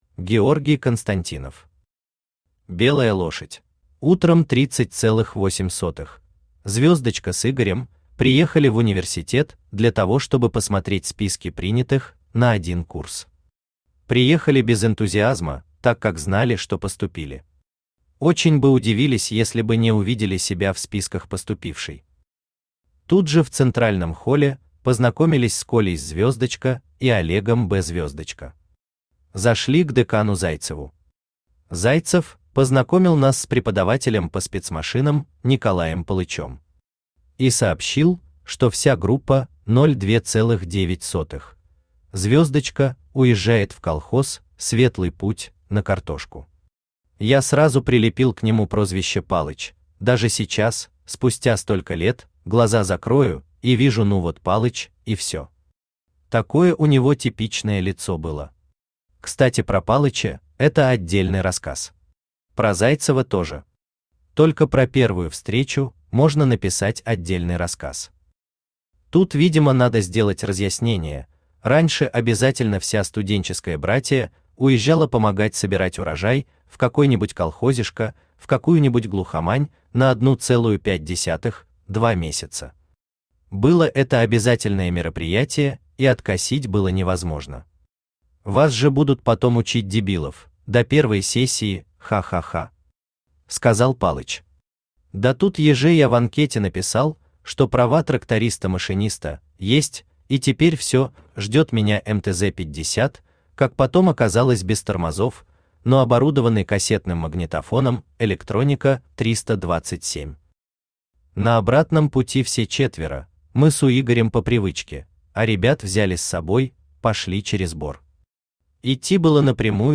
Аудиокнига Белая лошадь | Библиотека аудиокниг
Aудиокнига Белая лошадь Автор Георгий Константинов Читает аудиокнигу Авточтец ЛитРес.